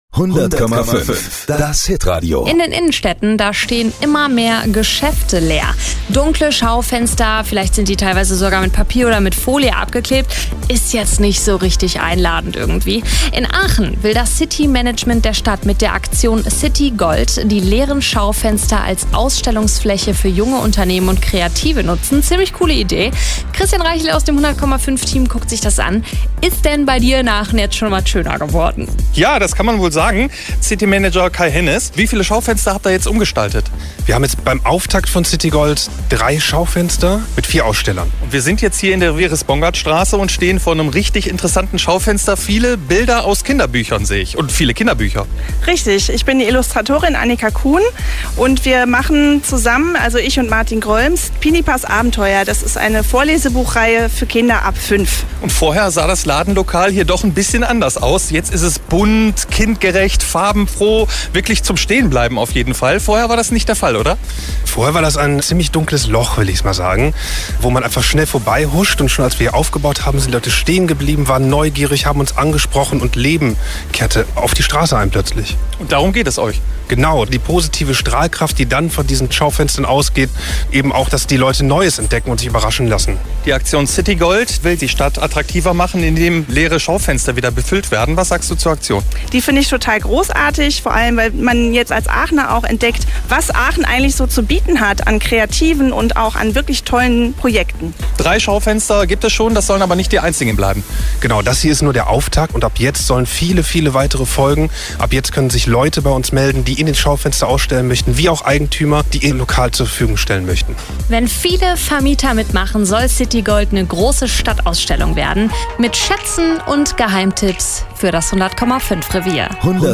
Zum Auftakt war die Presse da – und wir sind sogar im Radio bei 100,5 zu hören.